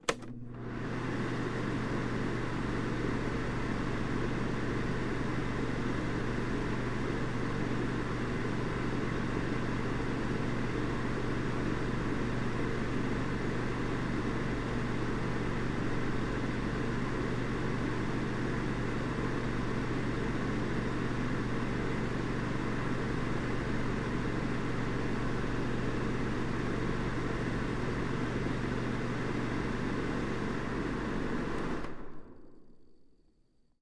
На этой странице собраны звуки работающего очистителя воздуха – монотонный белый шум, напоминающий легкий ветер.
Очиститель воздуха в рабочем режиме